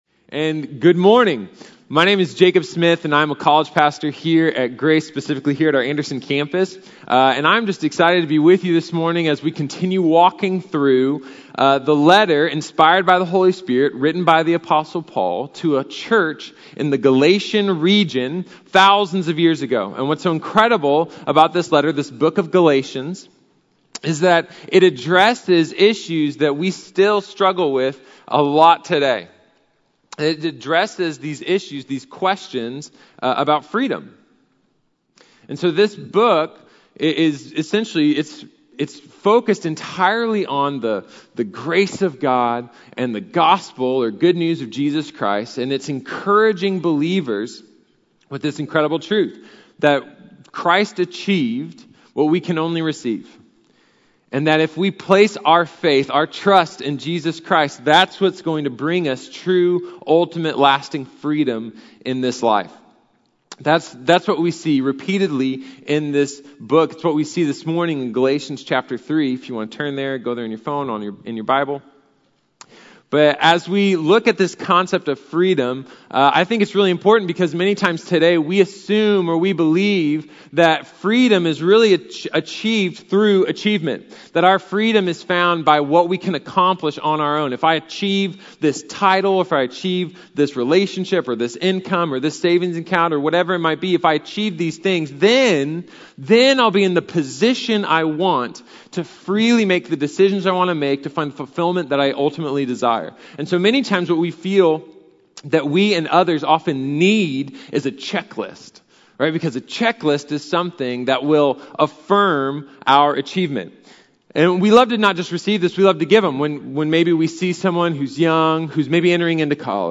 Clear Foundation | Sermon | Grace Bible Church